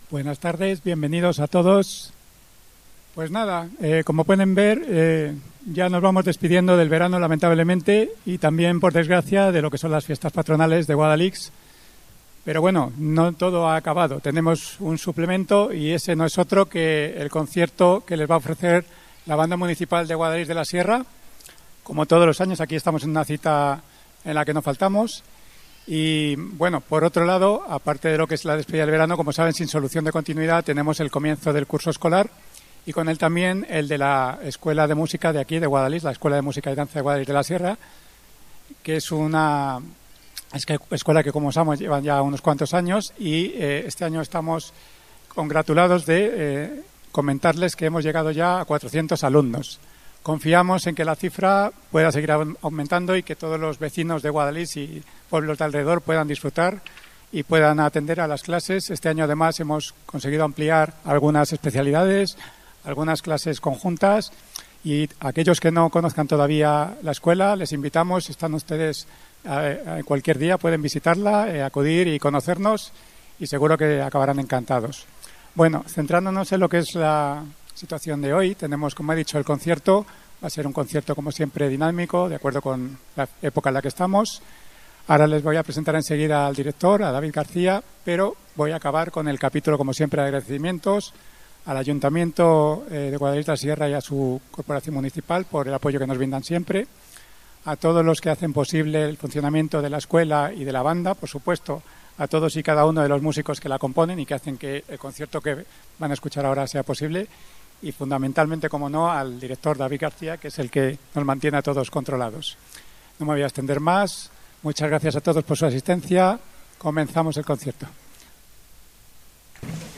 Audios de la Banda Municipal de Música de Guadalix
Concierto Fin fiestas Patronales 2025